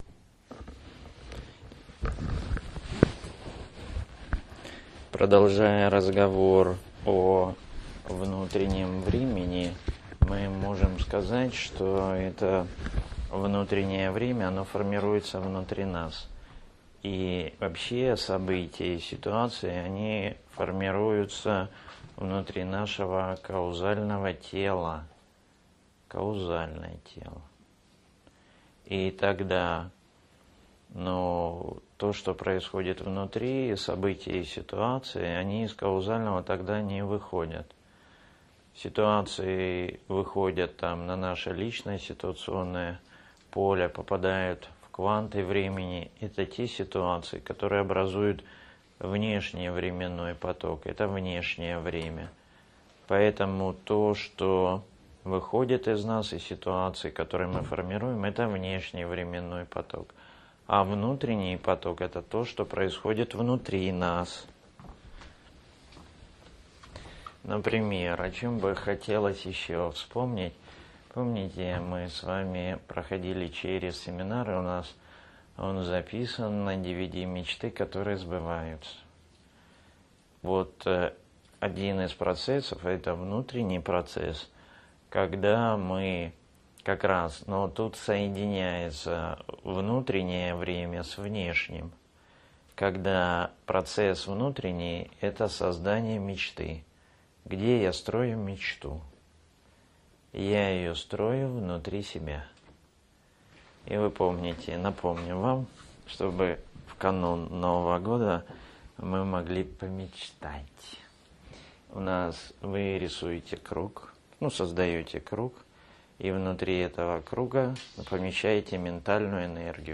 Лекции Семинар